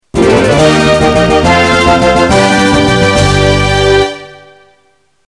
Sonneries » Sons - Effets Sonores » effet sonore de victoire